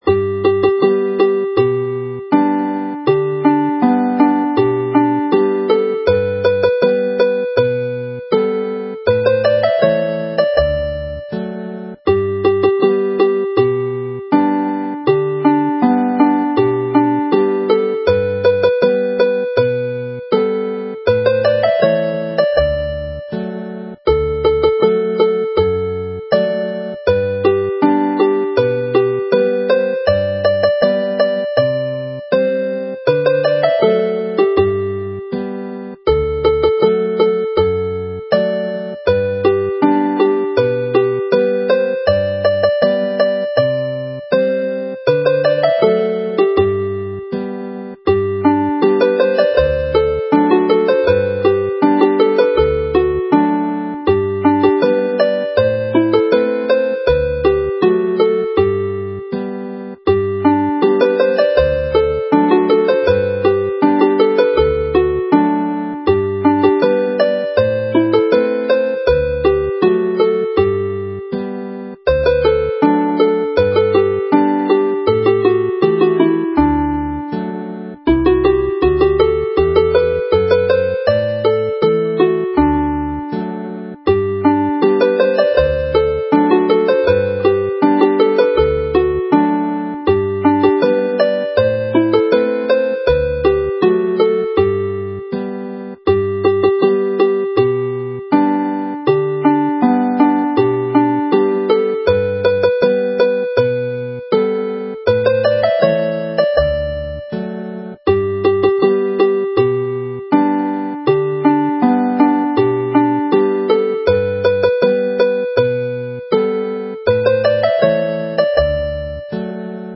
Play the set slowly